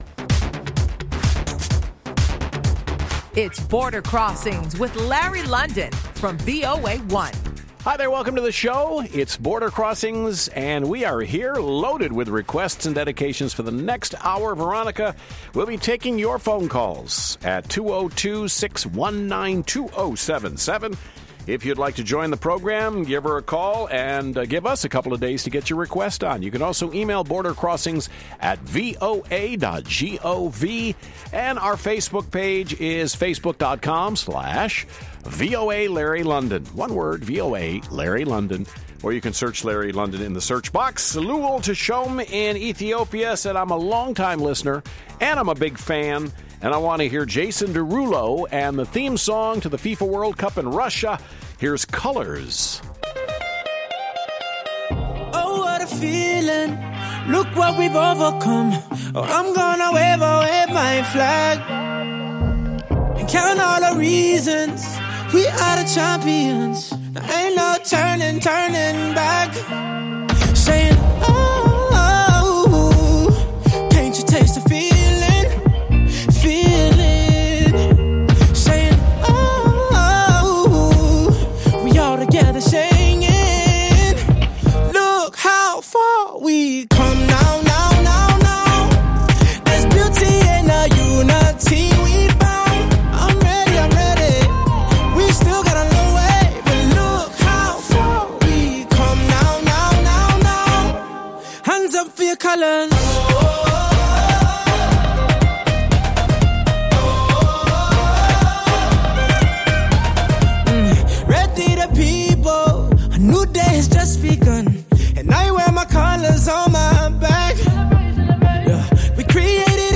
VOA’s live worldwide international music request show